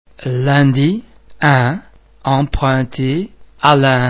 un um vowel base similar to ir in [sir]
The French [un] nasal vowel sound is made up of the [ oe ] vowel base which is subsequently nasalised by the air being passed through the mouth and the nostrils at the same time.